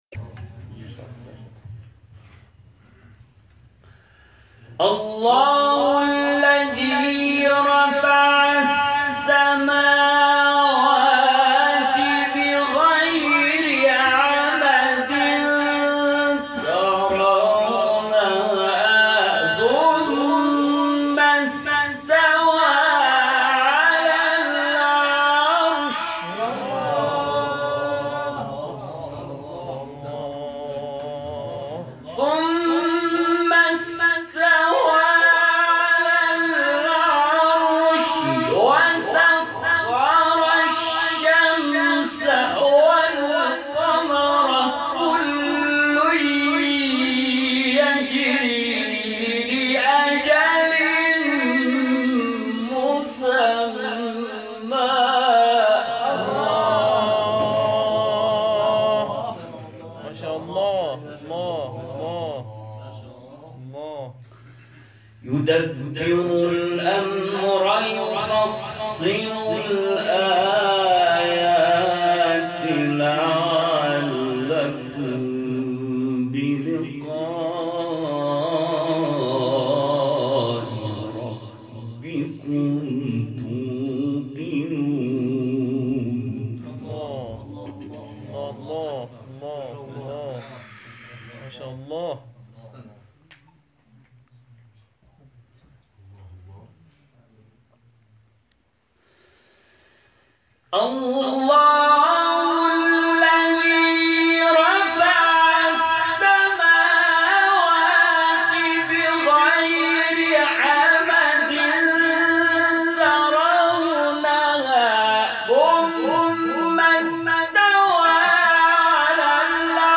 گروه شبکه اجتماعی: فرازهای صوتی از تلاوت قاریان بنام و ممتاز کشور را می‌شنوید.